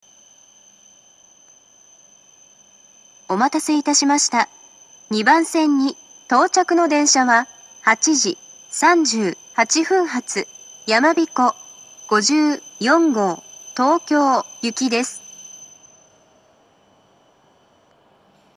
２０２１年２月上旬頃には、自動放送が合成音声に変更されました。
２番線到着放送